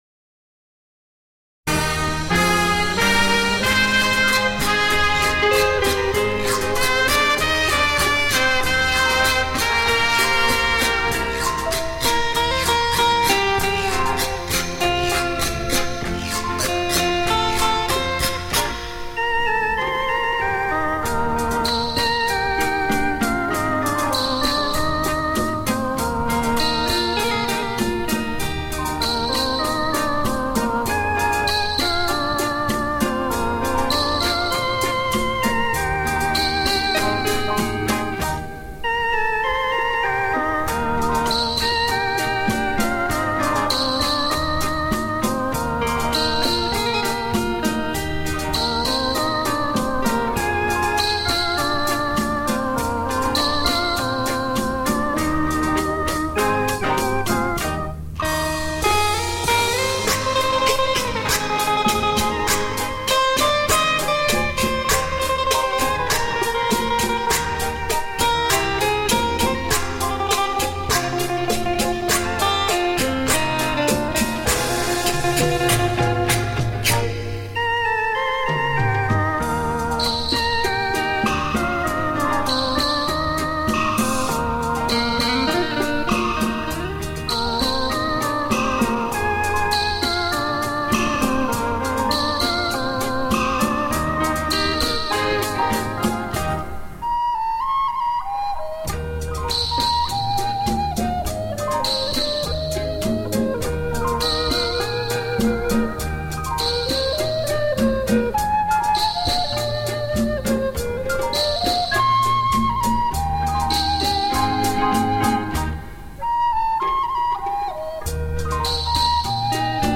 柔美恒久的音乐，带给您昔日的美好回忆。